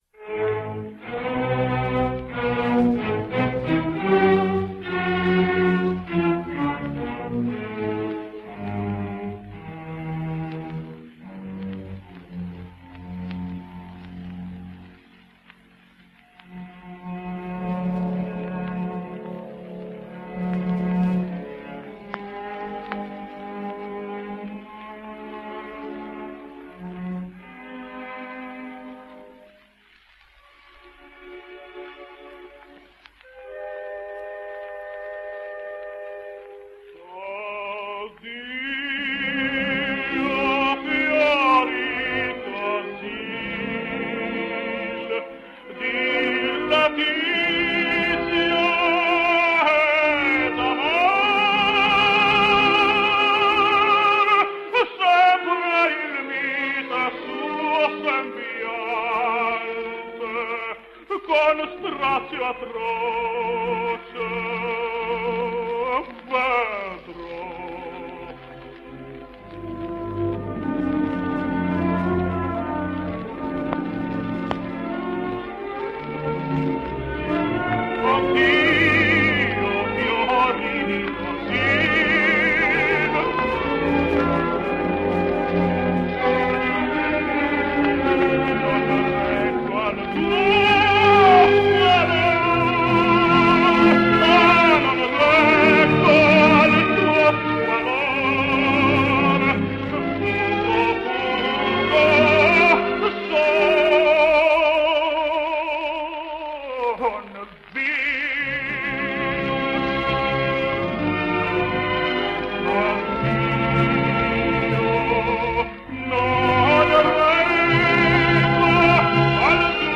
Greek tenor.
So why are we bothering with him, well he was a popular recording artist and he recorded for several companies, making both pre-electric and electric records.